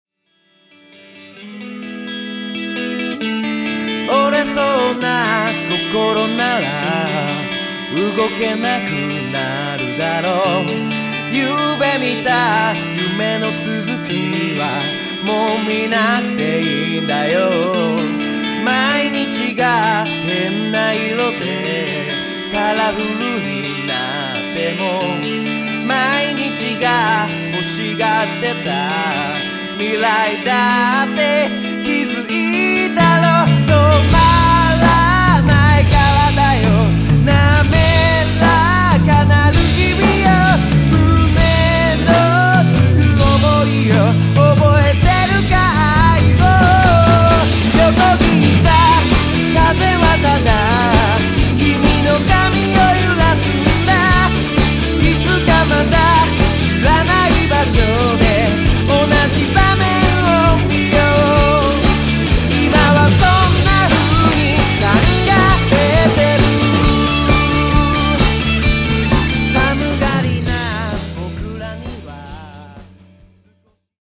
ストレートにボーカルをぶつけてくるポップロックバンド。